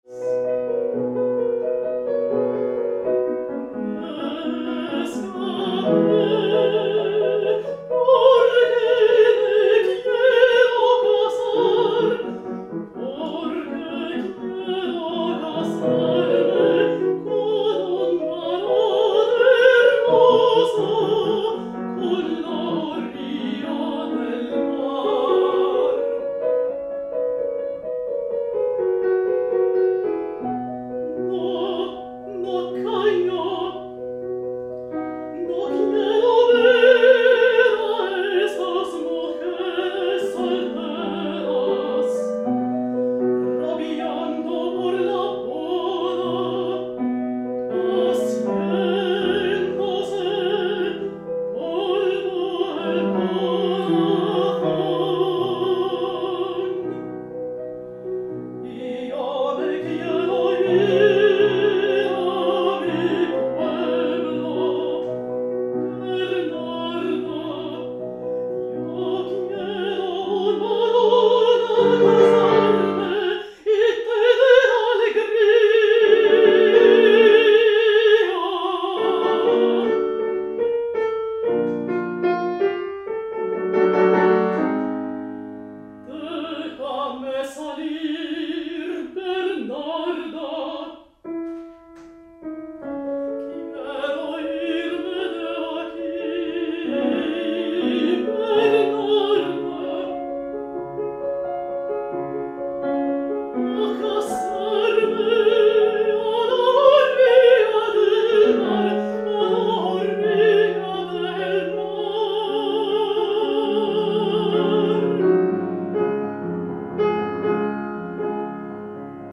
La Casa de Bernarda Alba is a one-act chamber opera in 3 scenes, duration 70min, based on the play by Federico Garcia Lorca.
Maria Josefa (contralto)
Orchestra:
Oboe/Cor Anglais
Clarinet/Bass Clarinet/Eb Clarinet
Horn
Harp
Percussion
2 Violas
2 Cellos
Double Bass